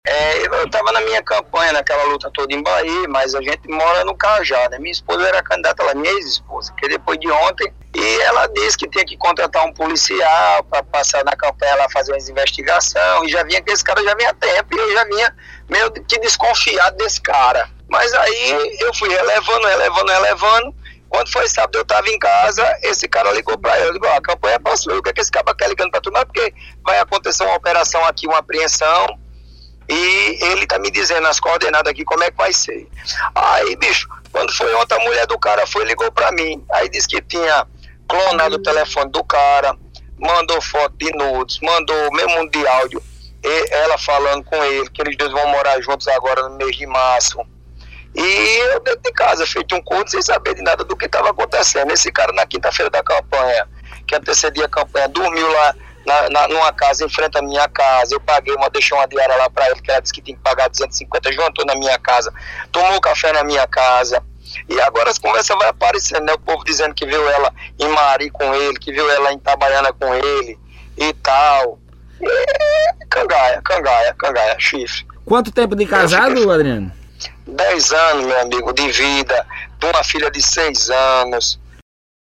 Em entrevista ao Correio Debate nesta quinta-feita (17), o vice-prefeito da cidade de Bayeux, Adriano Martins (MDB), contou detalhes de uma suposta traição que sofreu de sua esposa.
Em rede estadual, ele contou que foi traído e deu os detalhes.